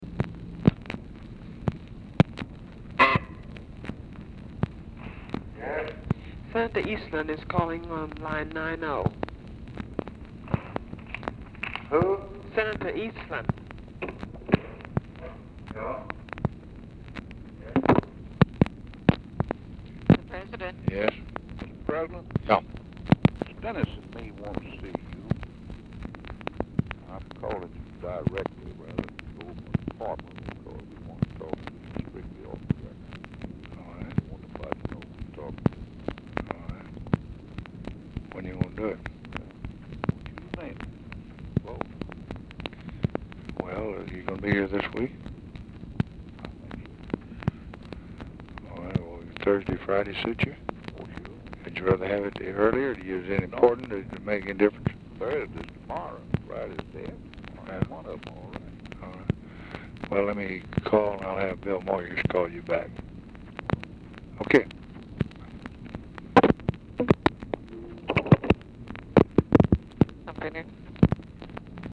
Telephone conversation # 2819, sound recording, LBJ and JAMES EASTLAND, 4/1/1964, 5:10PM
EASTLAND IS DIFFICULT TO HEAR
Format Dictation belt